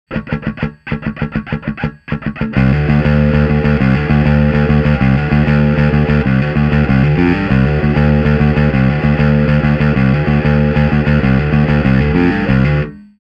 An extremely versatile, warm sounding transparent overdrive.
Smog on BASS
Amp: Ampeg SVT-CL
Cab: Ampeg SVT-810E
Bass: Fender Jazz ‘66